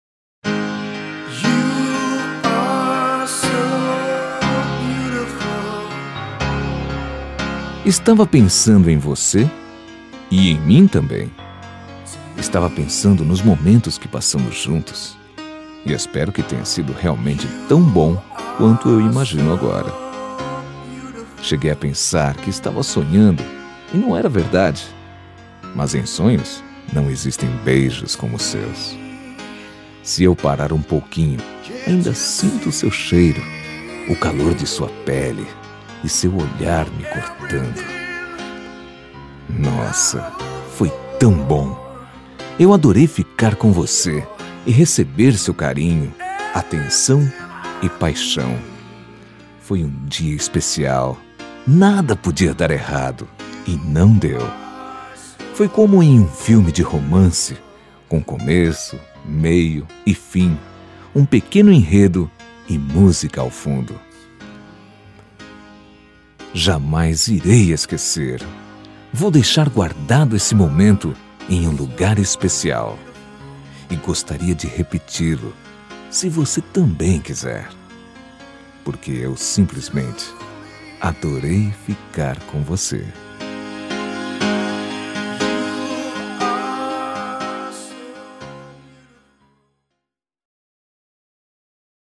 Telemensagens Românticas
Temas com Voz Masculina